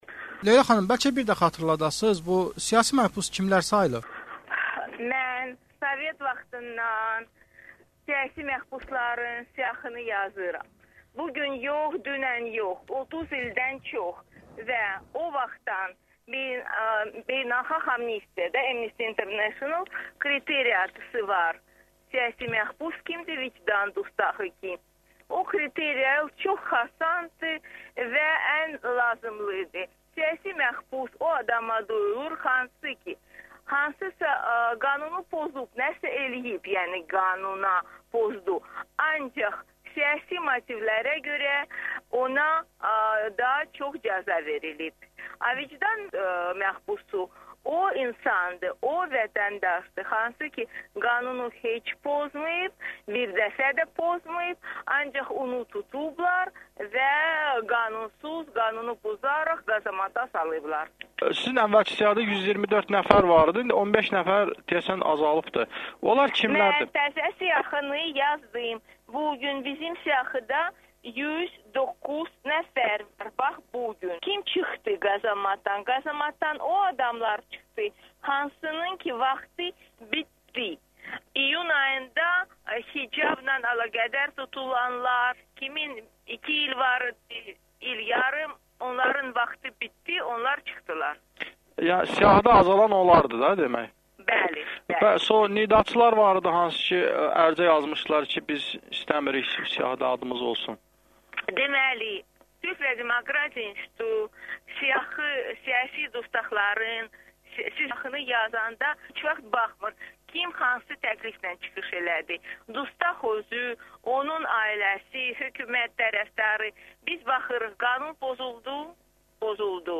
Sülh və Demokratiya İnstitutunun direktoru Leyla Yunus Azərbaycanda siyasi məhbusların yeni siyahısını təqdim edib. Onun AzadlıqRadiosuna müsahibəsini diqqətinizə yetiririk.